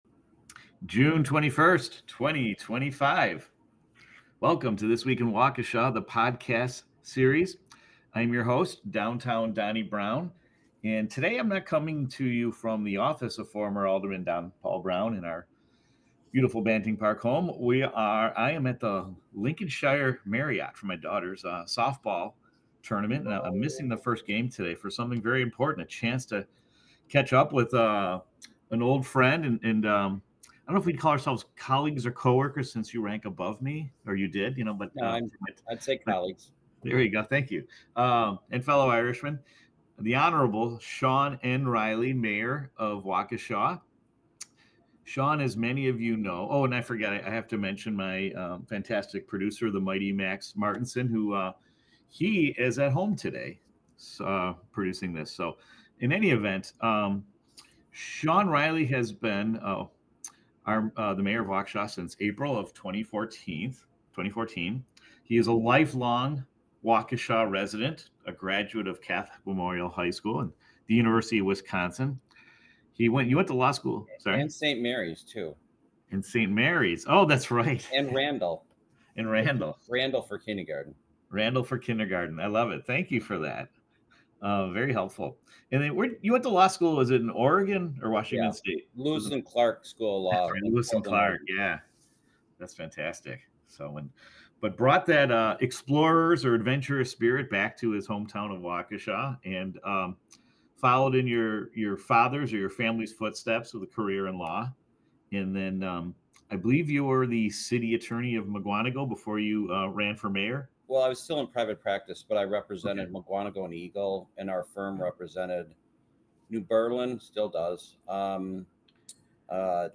As he wraps up his fourth and final term, Mayor Reilly reflects on his career in public service and his life as a proud son of Waukesha.